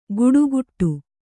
♪ guḍugu